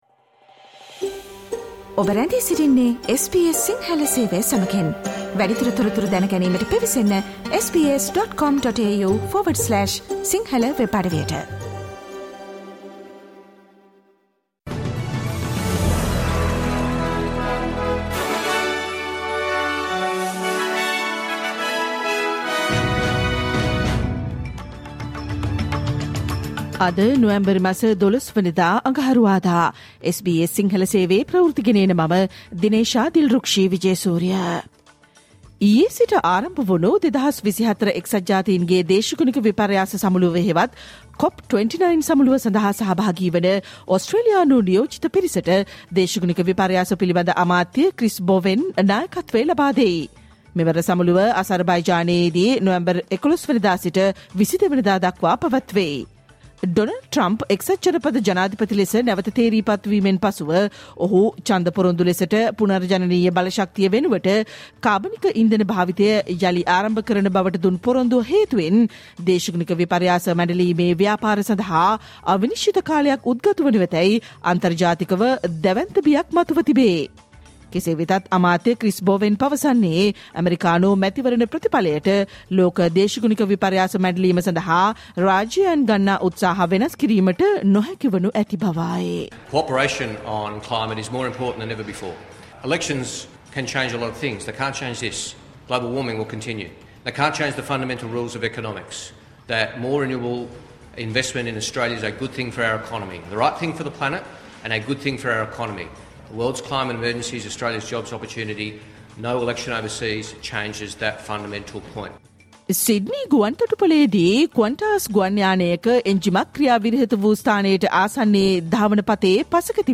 Australian news in Sinhala, foreign and sports news in brief.